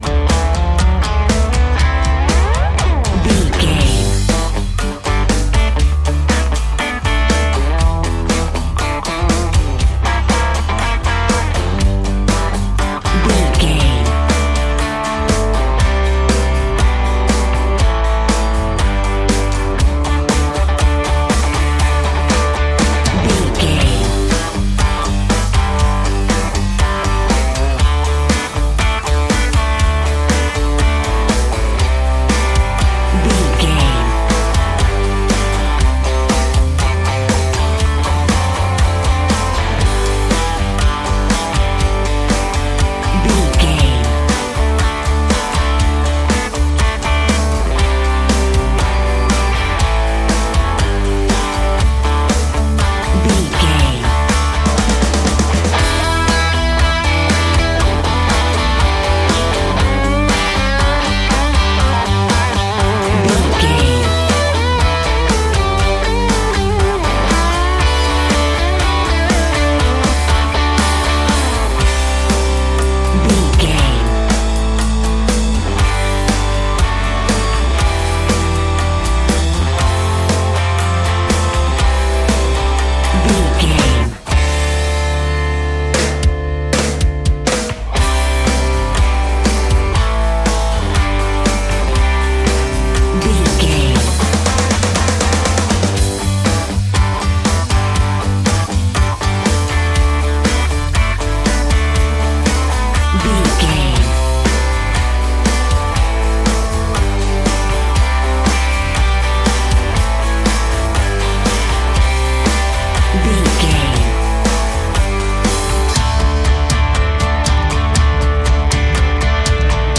Ionian/Major
electric guitar
drums
bass guitar